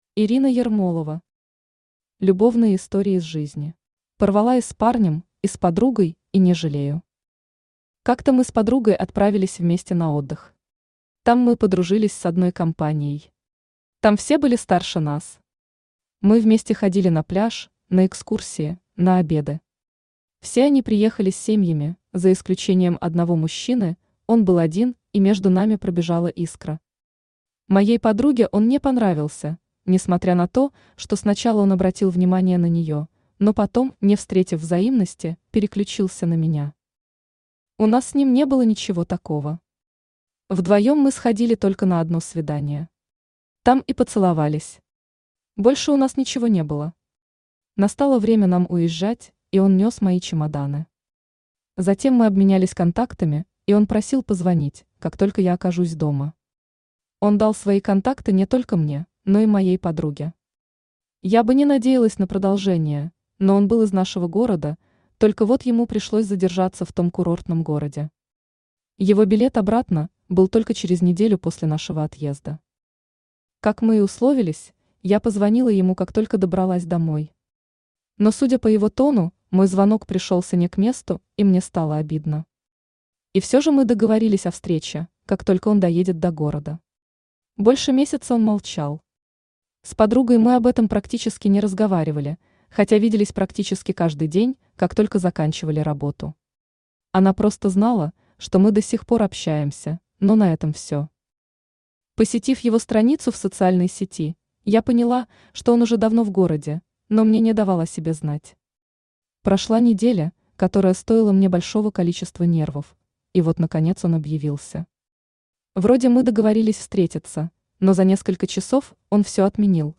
Аудиокнига Любовные истории из жизни | Библиотека аудиокниг
Читает аудиокнигу Авточтец ЛитРес